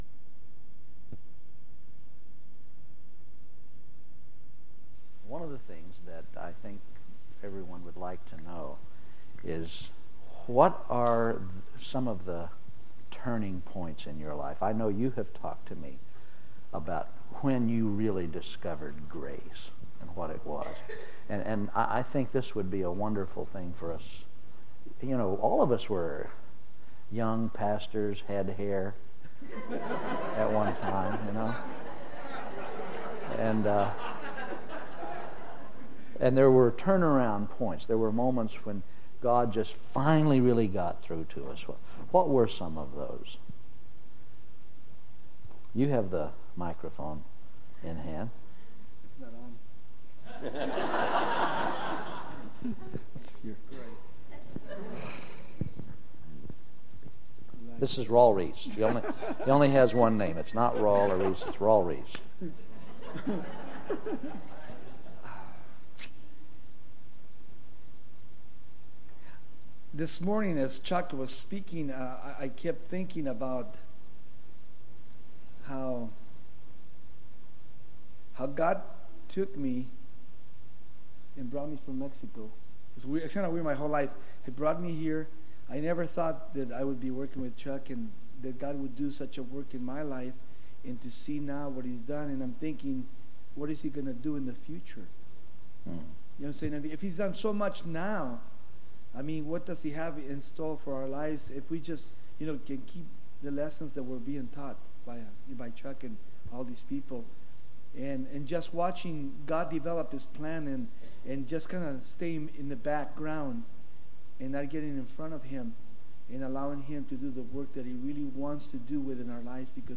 1998 DSPC: Session 6 – Q&A
Speaker: Chuck Smith
Conference: Pastors & Leaders